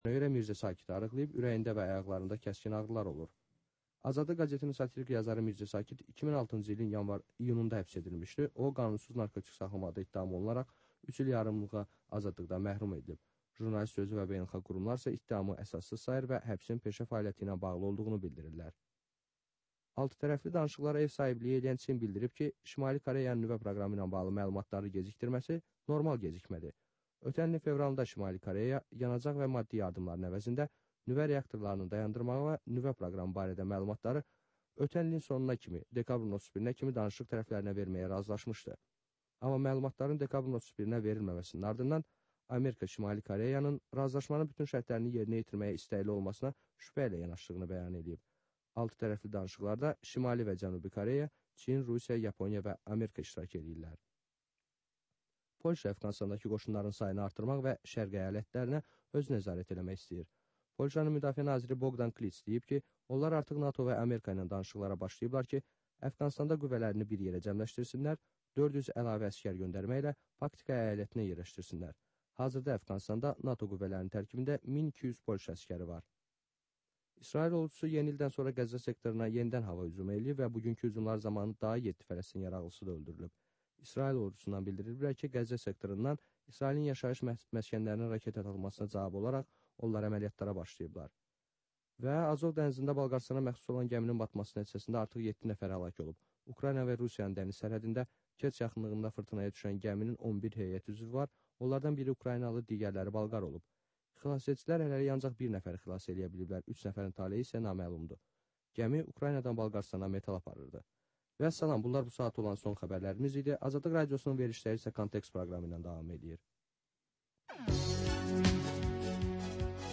Hadisələrin təhlili, müsahibələr və xüsusi verilişlər.